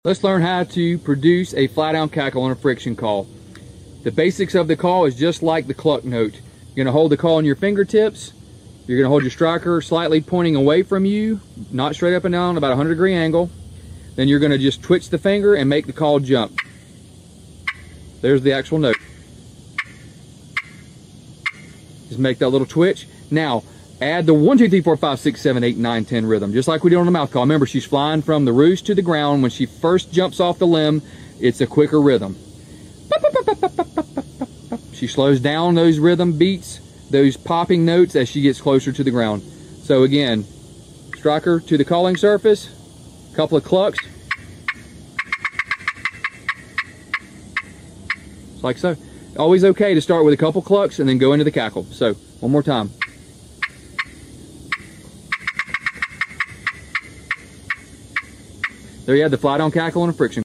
Master the fly down cackle with sound effects free download
Master the fly-down cackle with a friction call on the TurkeyTech mobile app found on the App Store.
Start with a couple of clucks, then bring it to life with a quick 1-10 rhythm as she flies from the roost to the ground.